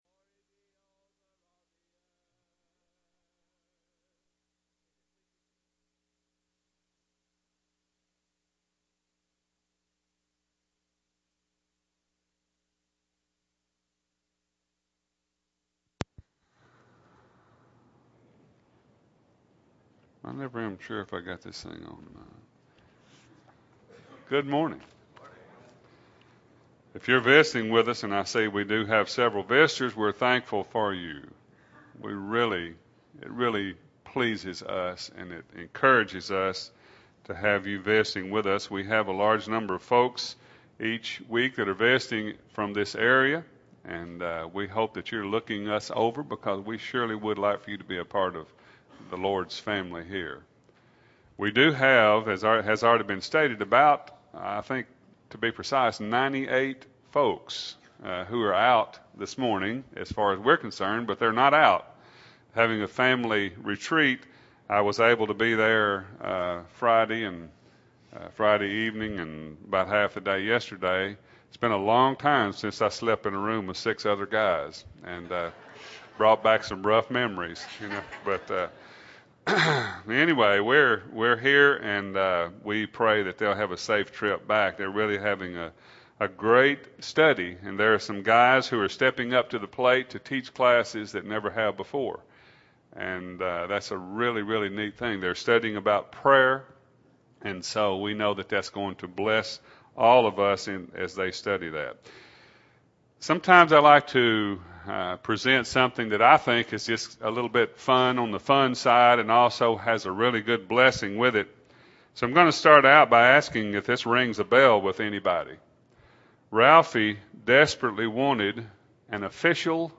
2008-06-22 – Sunday AM Sermon – Bible Lesson Recording